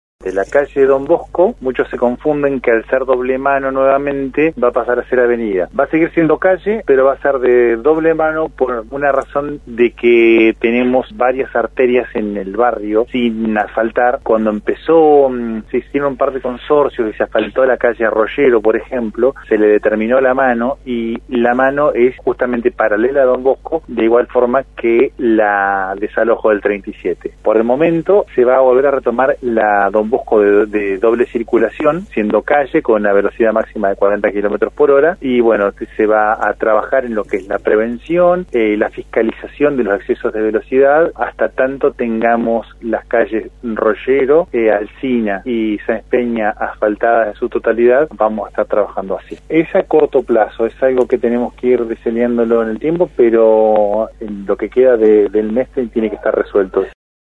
El Director de Transporte de la Municipalidad de Esquel, Diego Austin, en diálogo con el programa Postales de Radio que se emite por Nacional Esquel, adelantó que se analiza la posibilidad de cambiar el sentido de circulación de las calles San Martín, 9 de Julio y Rivadavia.